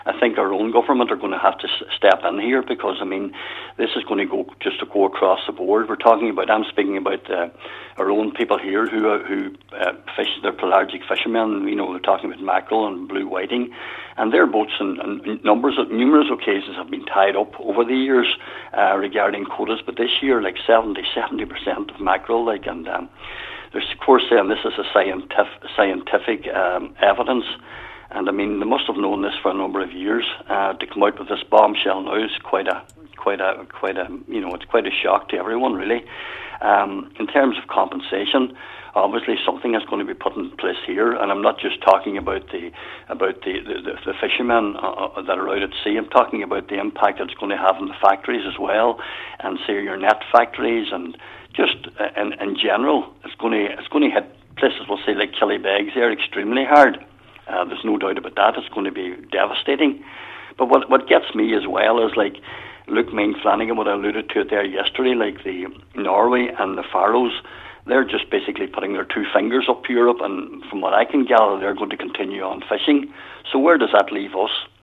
Cllr Farren questioned MEP Luke ‘Ming’ Flanagan at a recent meeting about whether there will be compensation for the people who will suffer as a result: